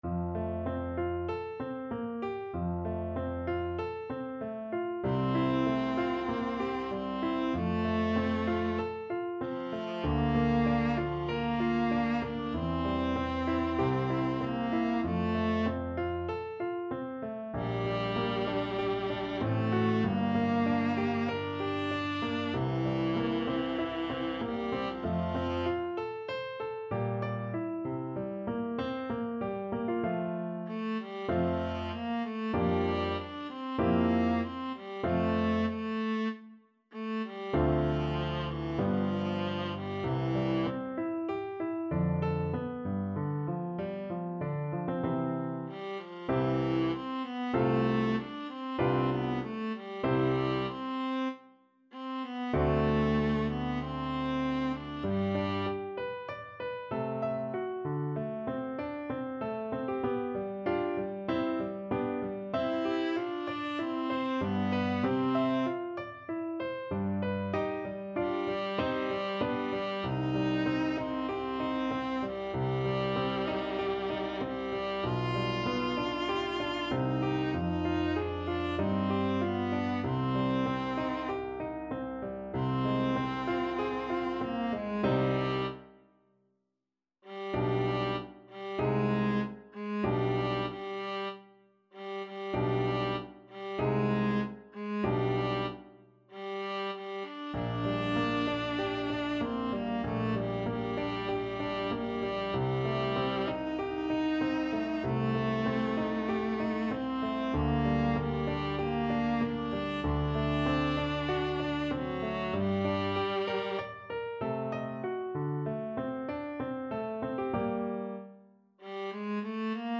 Classical Mozart, Wolfgang Amadeus Abendempfindung an Laura, K.523 Viola version
Viola
4/4 (View more 4/4 Music)
F major (Sounding Pitch) (View more F major Music for Viola )
~ = 96 Andante
E4-F5
Classical (View more Classical Viola Music)